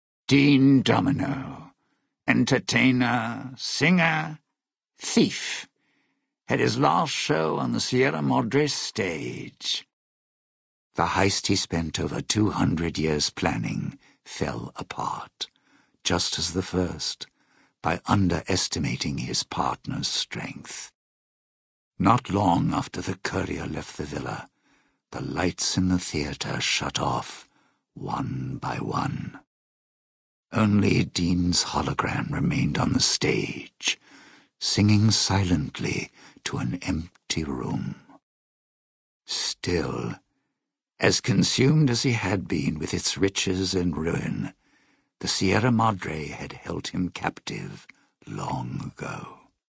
Category:Dead Money endgame narrations Du kannst diese Datei nicht überschreiben. Dateiverwendung Die folgende Seite verwendet diese Datei: Enden (Dead Money) Metadaten Diese Datei enthält weitere Informationen, die in der Regel von der Digitalkamera oder dem verwendeten Scanner stammen.